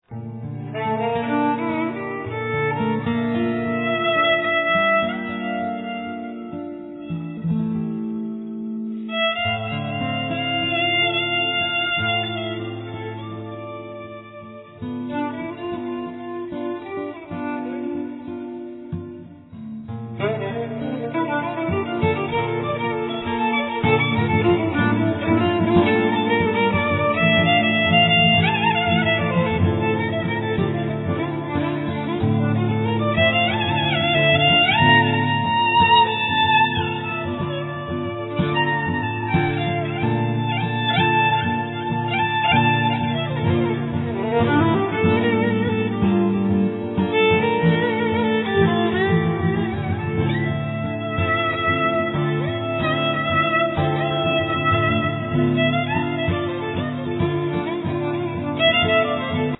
Violin, Viola
Didjeridoo, Zither, Tuned glass
Drums
Guitar